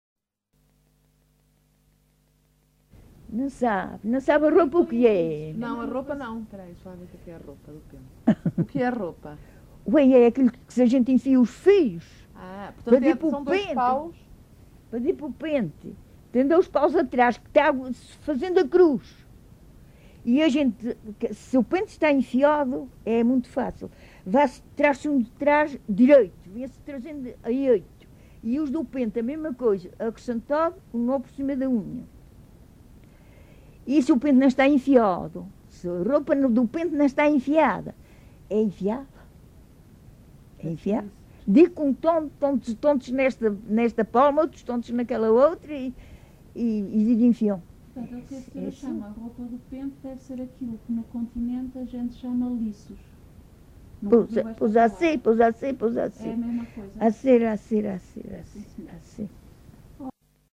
LocalidadeCosta do Lajedo (Lajes das Flores, Horta)